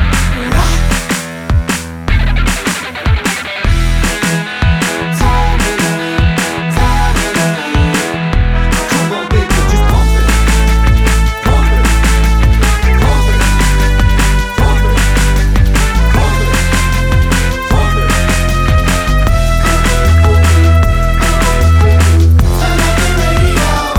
no Backing Vocals R'n'B / Hip Hop 3:35 Buy £1.50